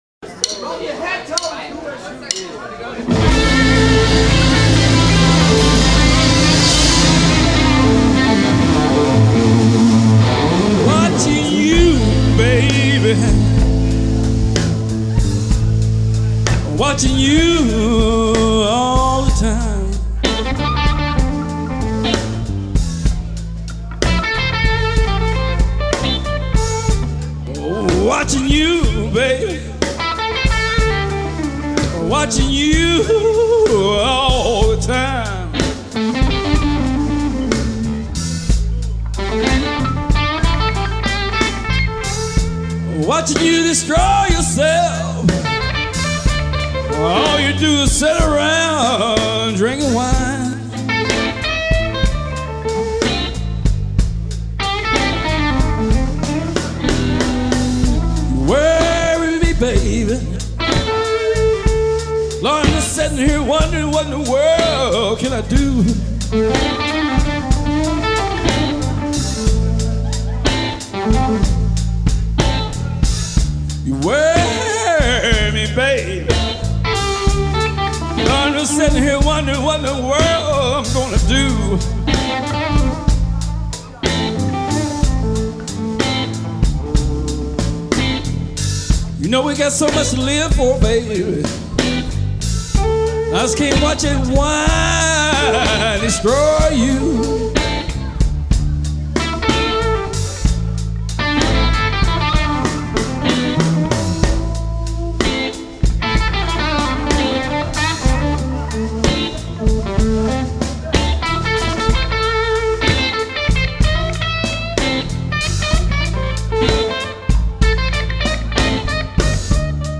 Hier der AC15 mit einer Gibson LP, Clubgig. Hängt noch ein delay davor, das wars. Hall kommt vom Amp. gemiked mit einem SM58